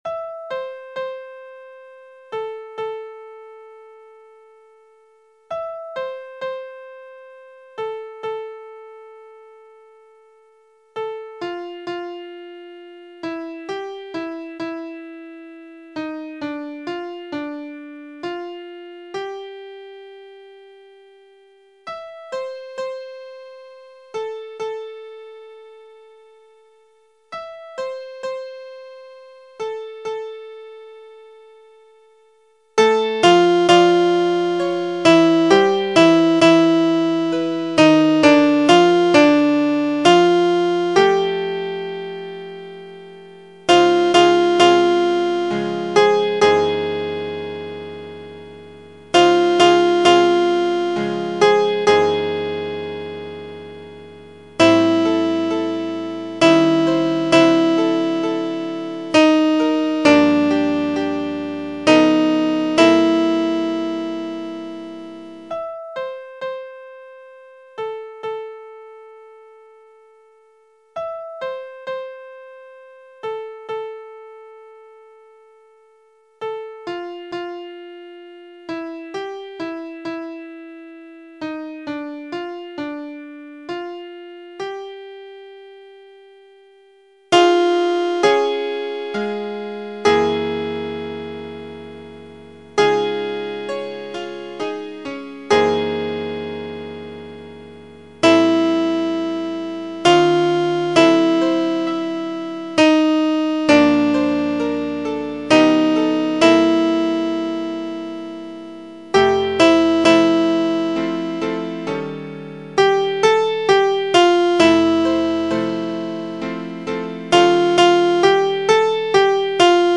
Christmas Time is Here (arr. Alexander)      Your part emphasized:     Sop 1   Sop 2
Alto 1